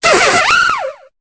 Cri de Rototaupe dans Pokémon Épée et Bouclier.